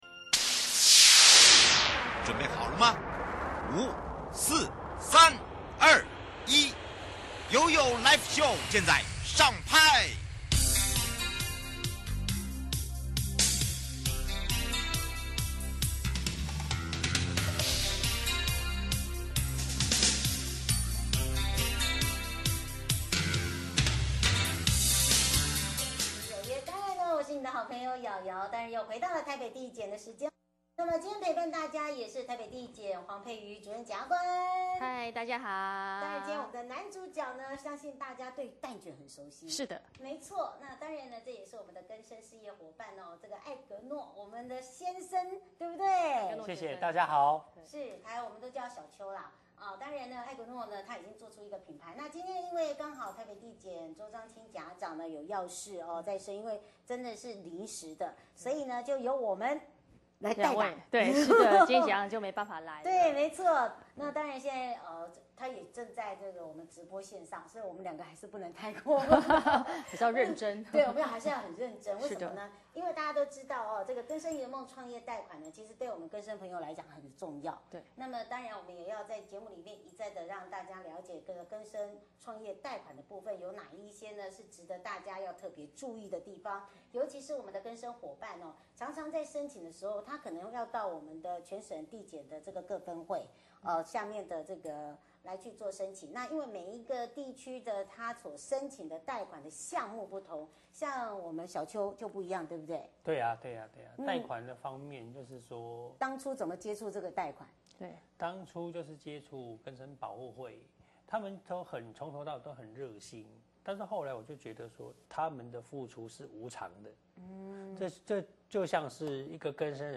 (直播) 節目內容： 什麼是國民法官?什麼案件,會讓國民法官審理?誰可以當國民法官?國民法官怎麼產生?我可以拒絕當國民法官嗎 ?國民法官有報酬嗎 ?可以請公假嗎 ?